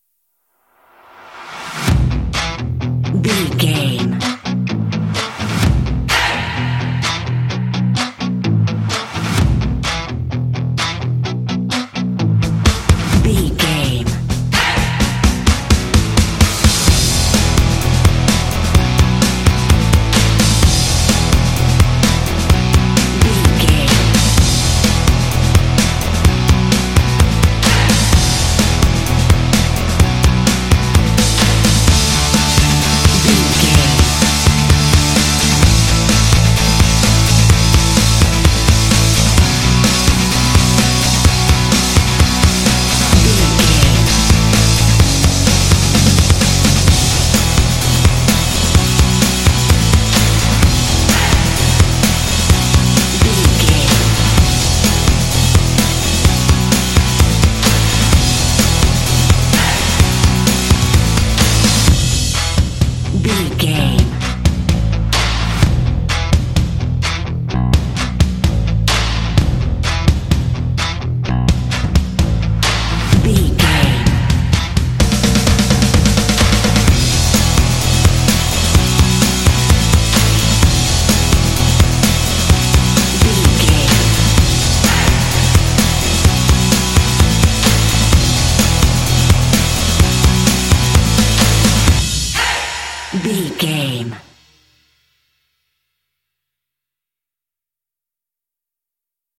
This indie track contains vocal “hey” shots.
Ionian/Major
lively
cheerful/happy
drums
bass guitar
electric guitar
percussion
synth-pop
alternative rock
indie